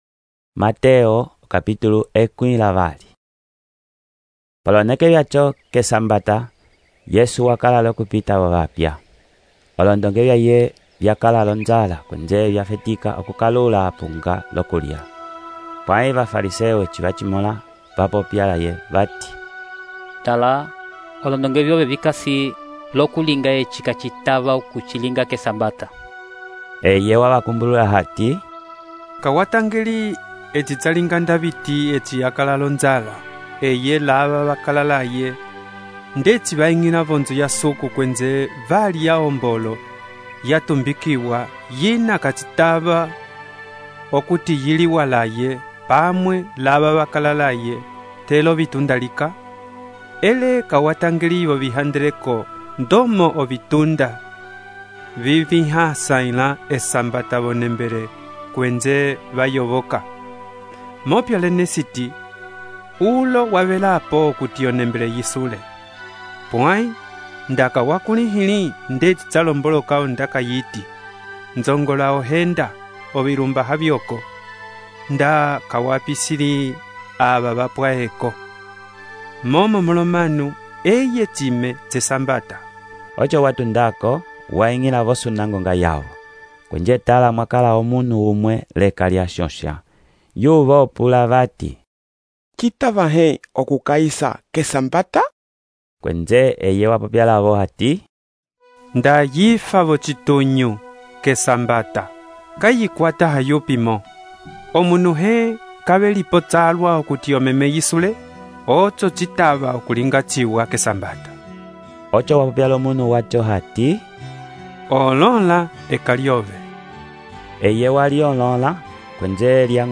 texto e narração , Mateus, capítulo 12